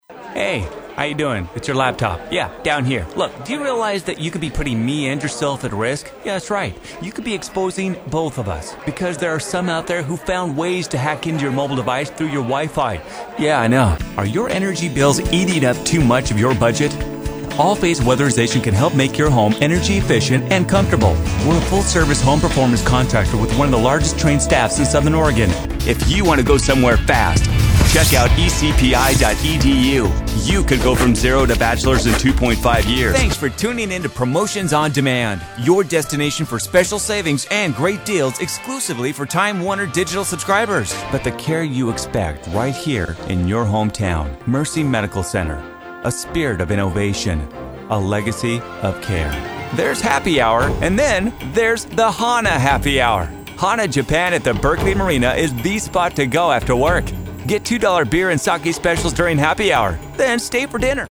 young, hip, cool, youthful, energetic,smooth, caring,fun,guy next door, announcer guy, excited, Television Imaging Voice, Radio Station Imaging Voice, Promo Voice Overs.
englisch (us)
Sprechprobe: Werbung (Muttersprache):